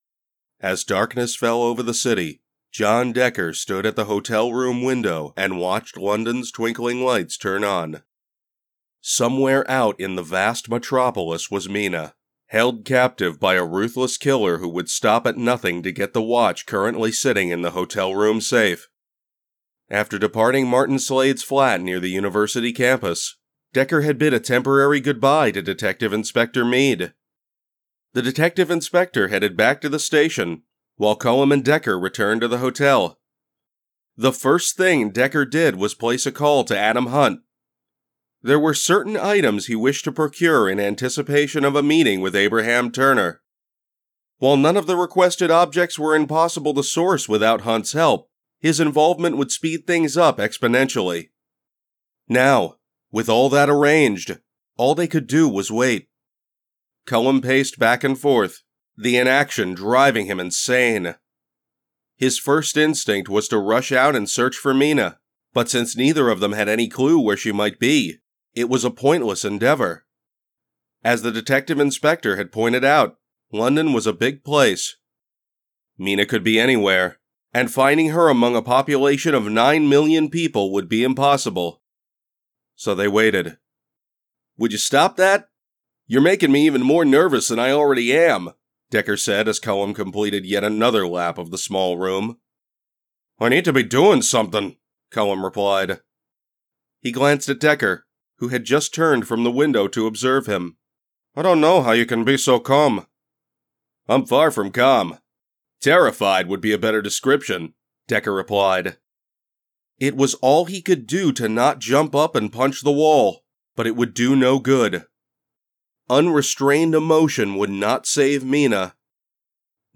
When I talk, people listen, and I can lend that gravitas to your story with a rich and clear sound.
Audiobook Sample 3